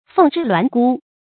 凤只鸾孤 fèng zhī luán gū 成语解释 只：单独。